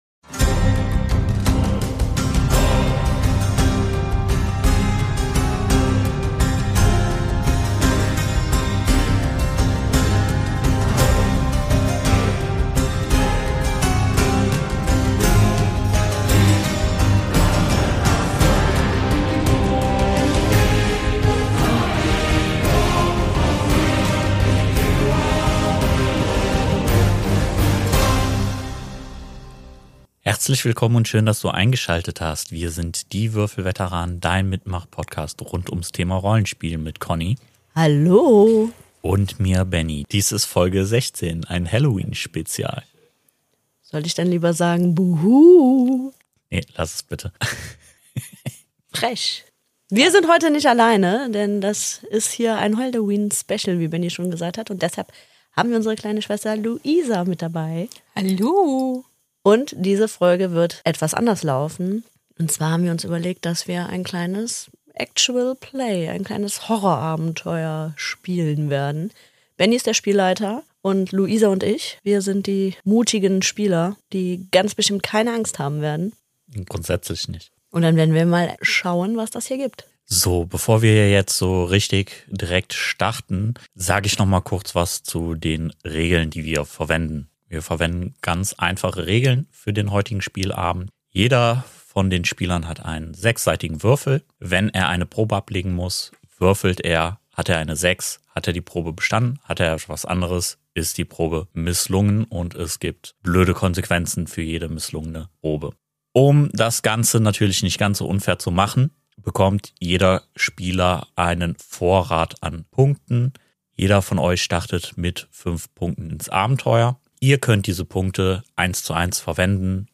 Ein kleines Actual Play. Sprich: Wir spielen einen kleinen OneShot für euch.